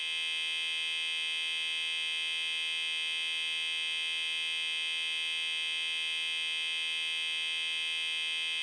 BallastHumMedium2.wav